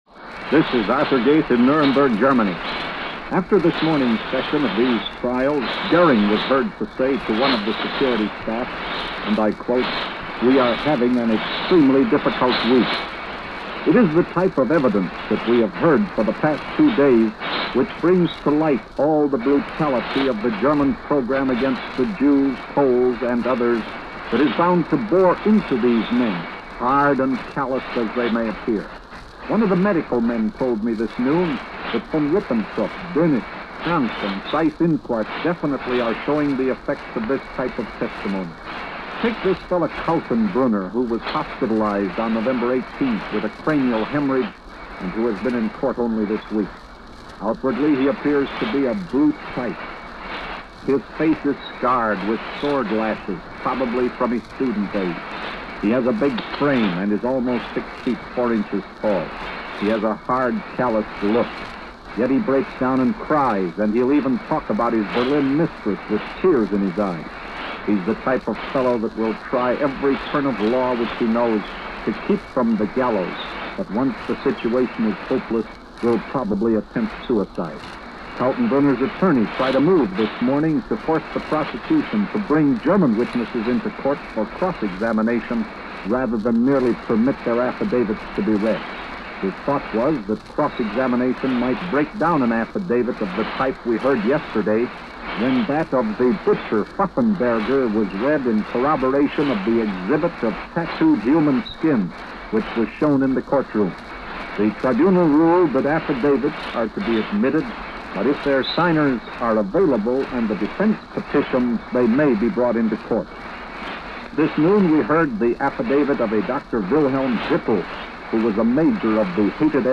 The sound is bit distant in places, as it was a shortwave report, which was par for the course at the time.